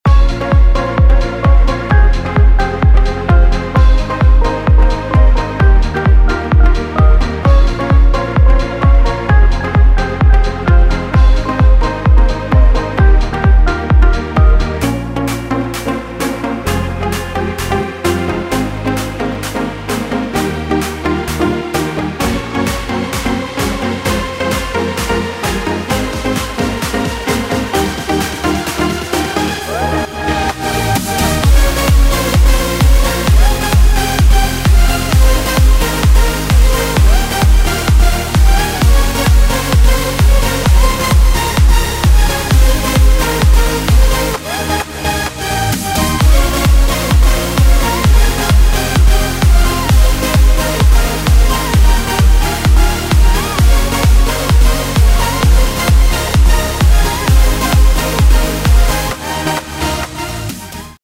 ז'אנרDance
BPM130